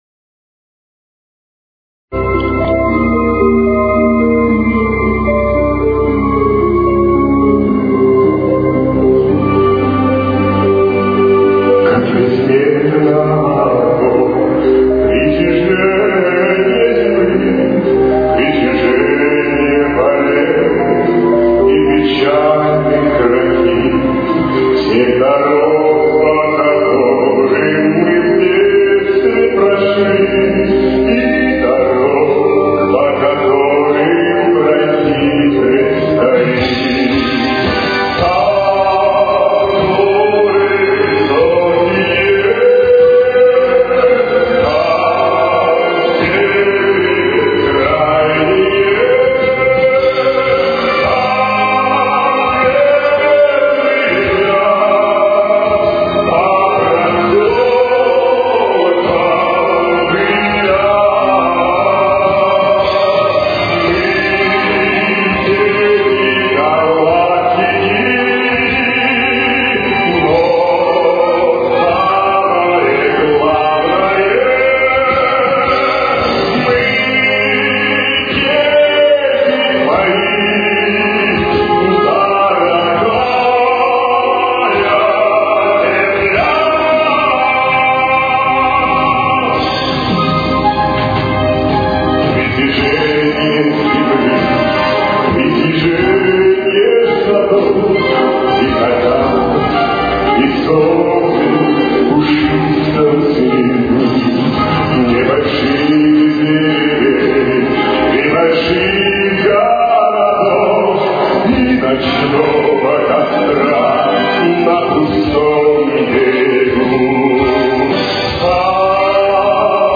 Темп: 115.